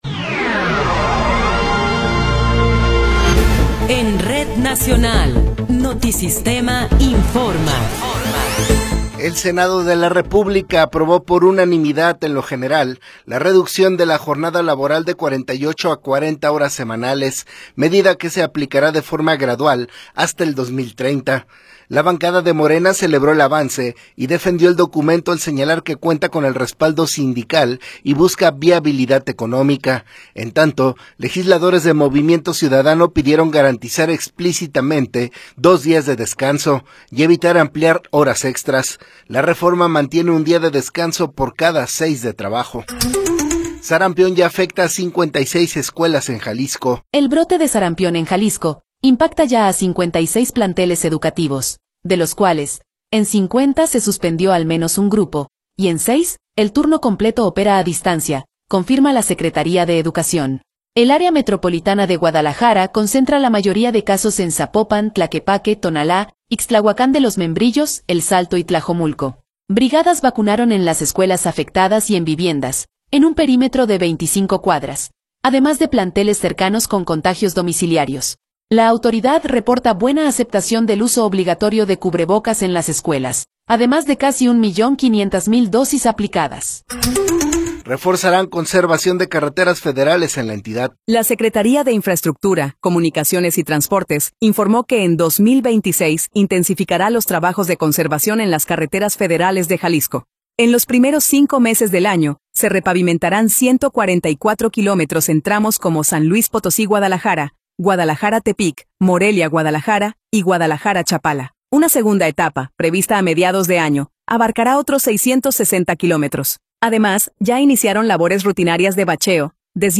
Noticiero 18 hrs. – 11 de Febrero de 2026
Resumen informativo Notisistema, la mejor y más completa información cada hora en la hora.